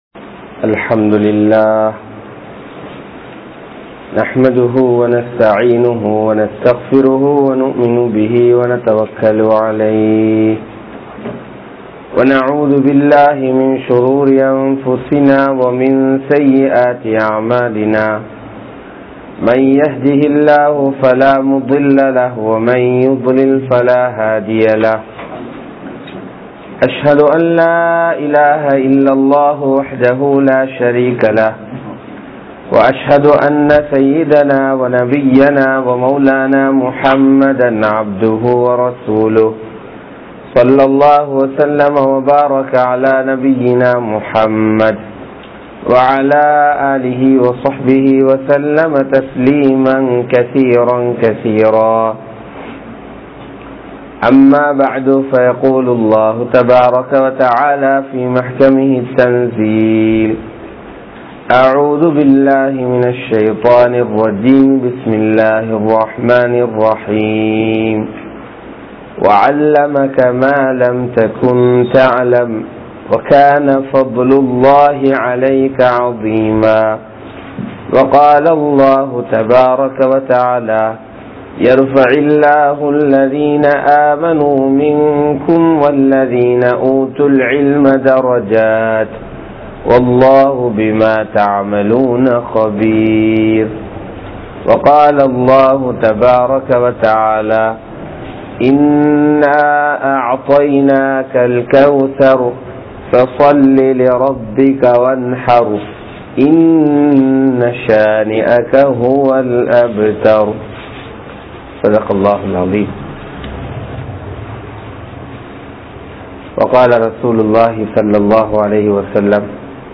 Pengalin Kadamaihal (பெண்களின் கடமைகள்) | Audio Bayans | All Ceylon Muslim Youth Community | Addalaichenai